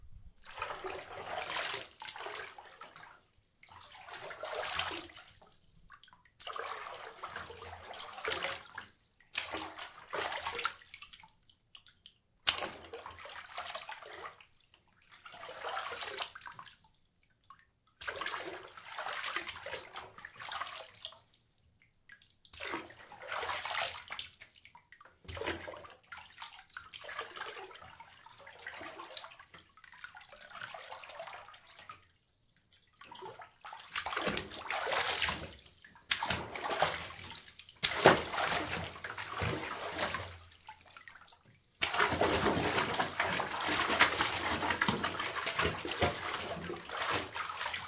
Freesound项目 " 浴盆溅水
描述：在一个完整的浴盆里泼水。
Tag: 飞溅 浴室浴缸